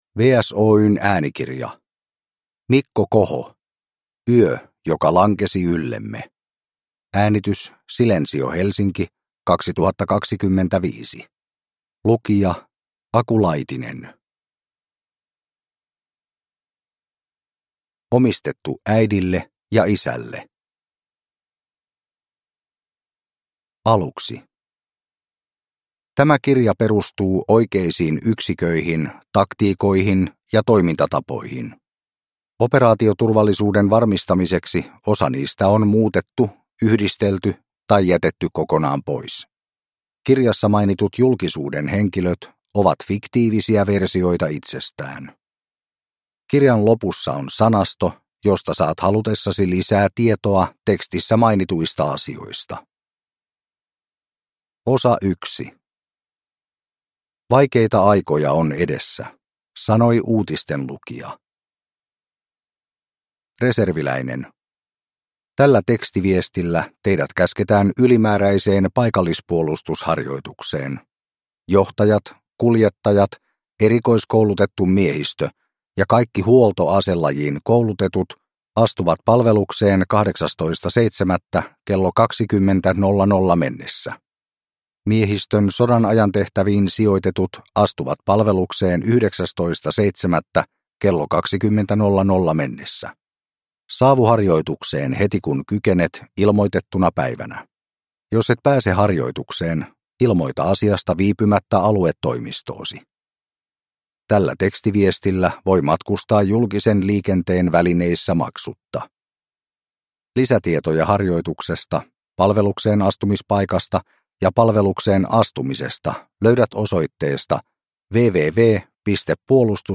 Yö joka lankesi yllemme (ljudbok) av Mikko Koho